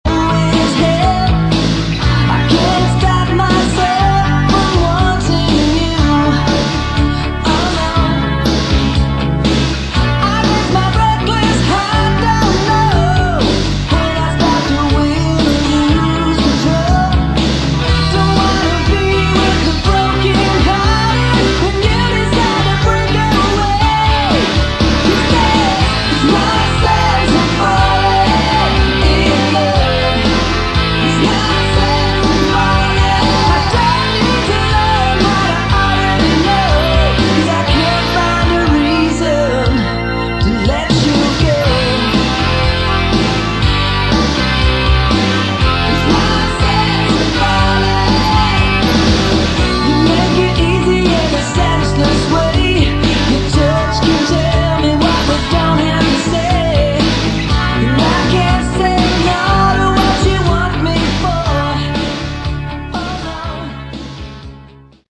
Category: AOR / Melodic Rock
Guitar, Lead Vocals
Bass, Vocals
Drums
Keyboards